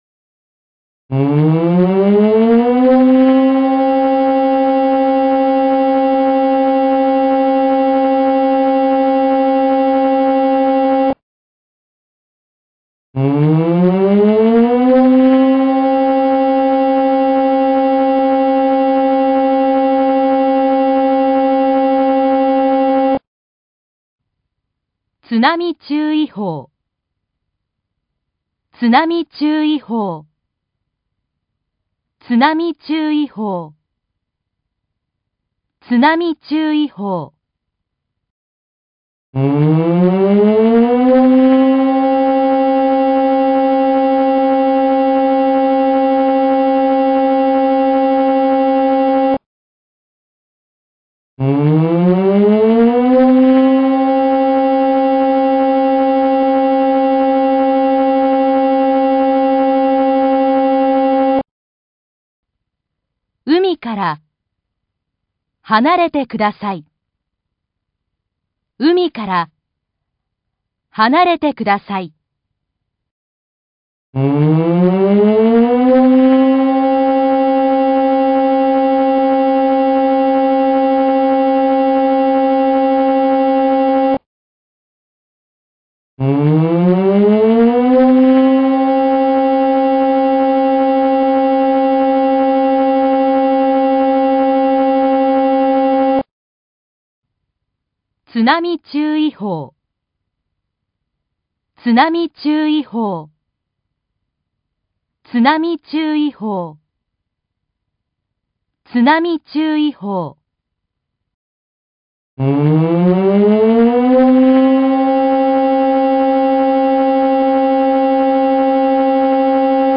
根據氣象廳發布的大海嘯警報、海嘯警報、海嘯警報,設置的室外揚聲器將自動播放海嘯警報、避難勸告等緊急資訊。
1. 警報(2次)
約10秒(約2秒秋)
※第5汽笛只在第3局播放。
海嘯注意報音源(0.2m<海嘯的預測高度≤1m)(音樂文件(MP3):4776KB)